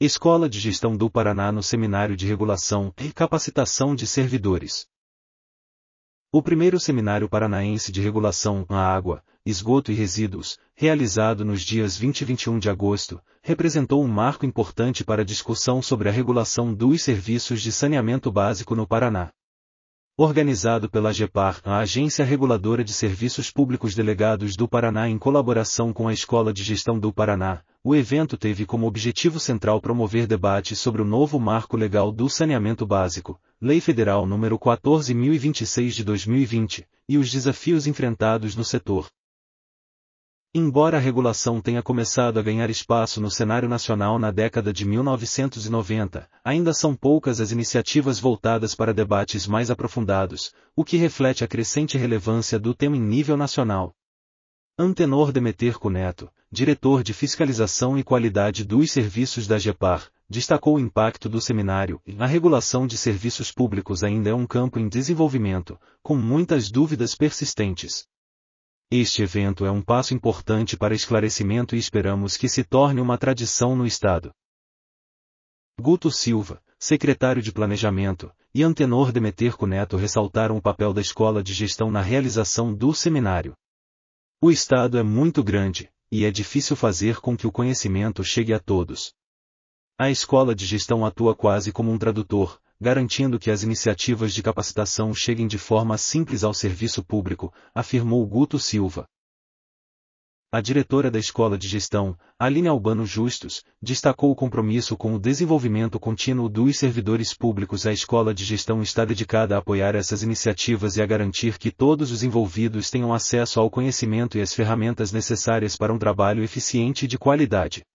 audionoticia_seminario_de_regulacao.mp3